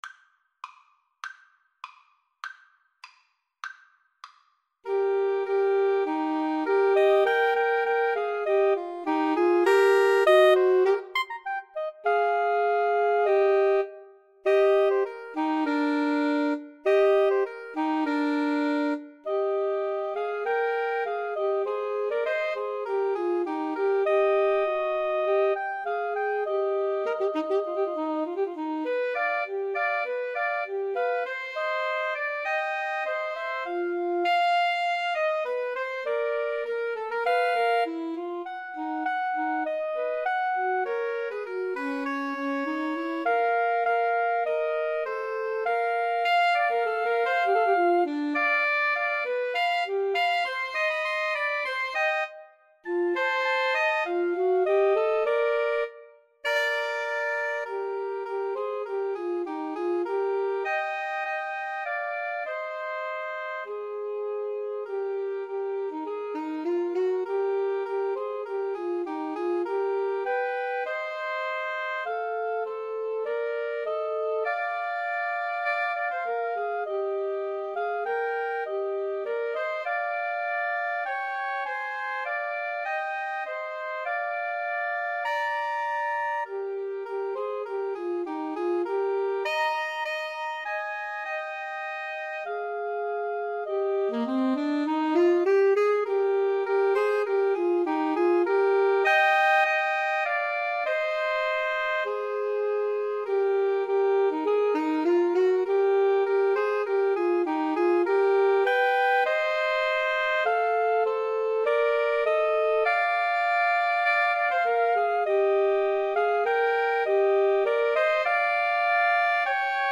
Soprano SaxophoneAlto SaxophoneTenor Saxophone
2/4 (View more 2/4 Music)
Tempo di Marcia
Pop (View more Pop Woodwind Trio Music)